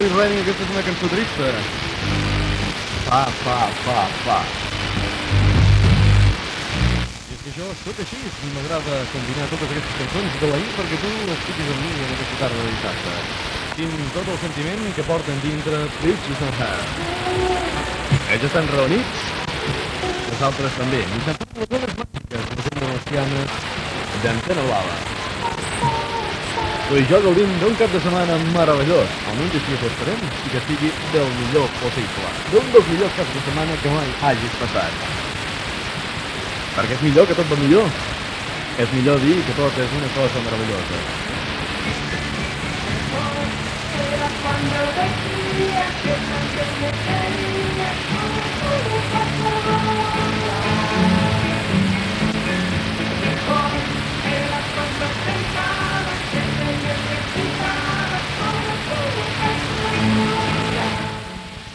Tema musical amb identificació.
Qualitat del so deficient